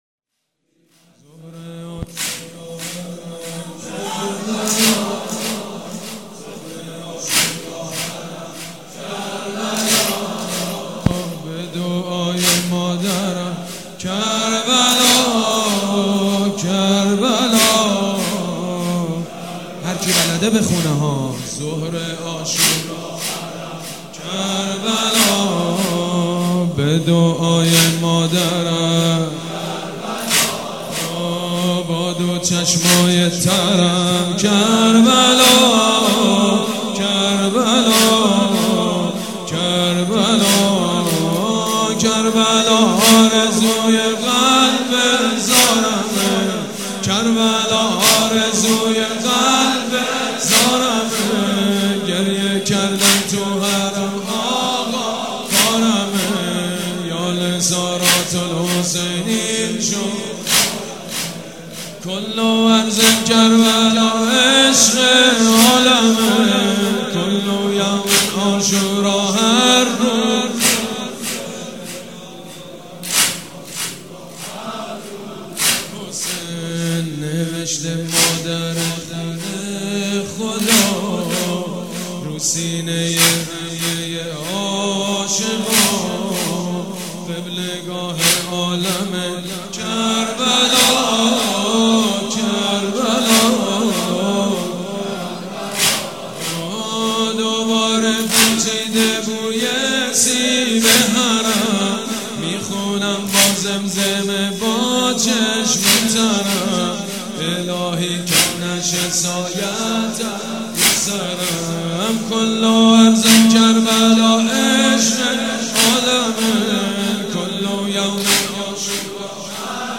«شهادت امام جواد 1394» واحد: ظهر عاشورا، حرم کربلا